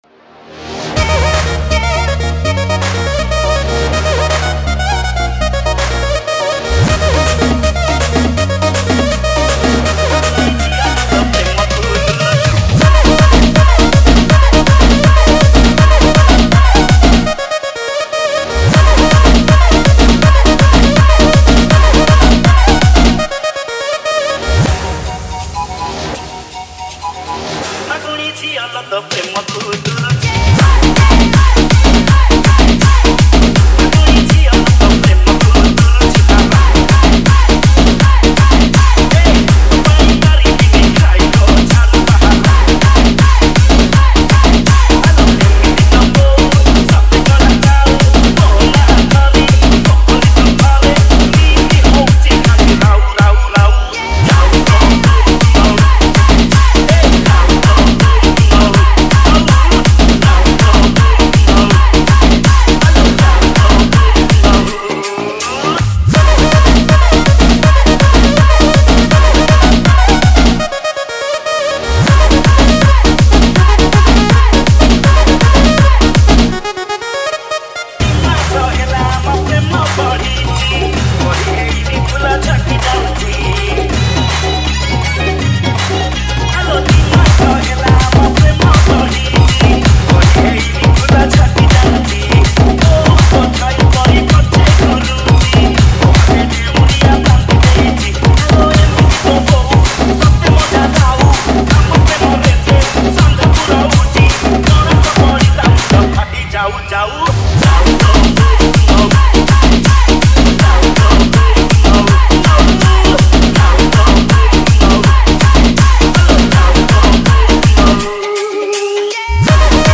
DANCE RMX